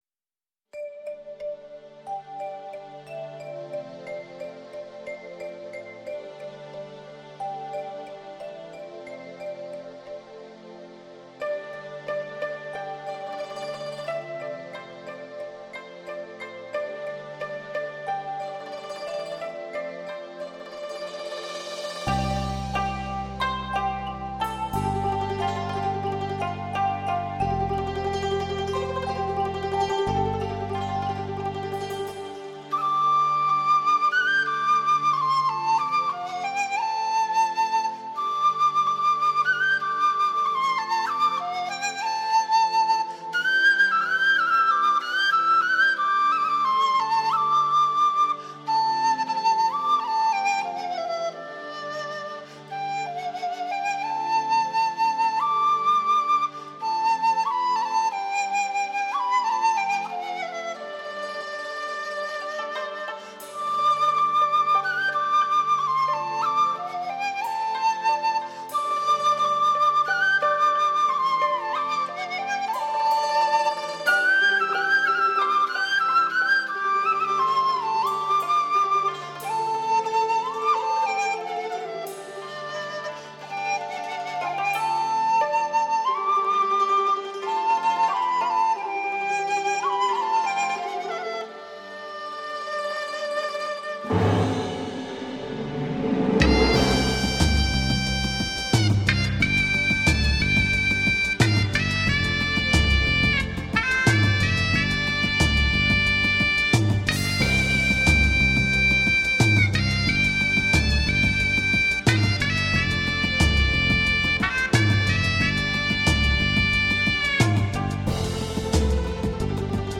运用敲击乐与真乐器的巧妙结合，使得中国民谣焕然一新。
录音十分细腻，线条明快，质感晶莹剔透，低频（暗涌）甚多，高频空气感深阔，气氛和音色都十分出色。
乐曲充分运用了排箫流畅、空旷、深远的音色和特点
在主题再现时加入新时代流行元素，使乐曲思绪如波浪般层层起伏，情义无穷。